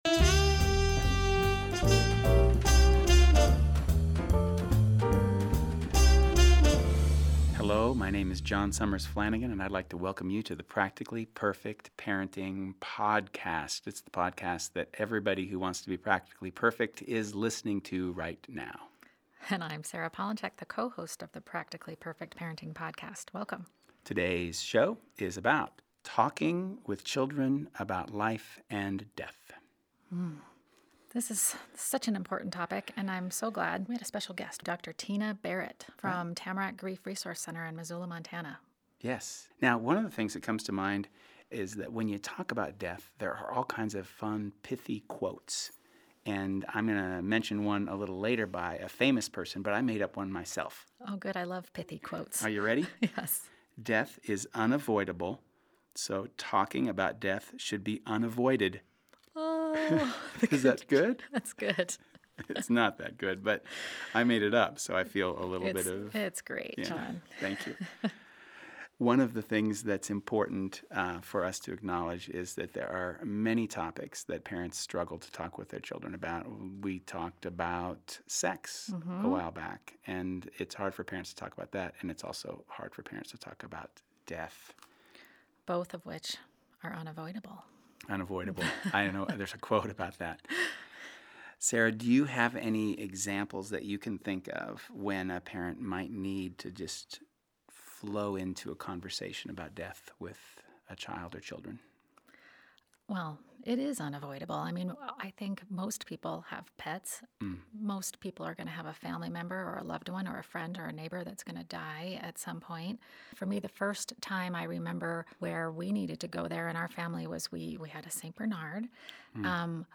Best of all, sandwiched in the middle is an interview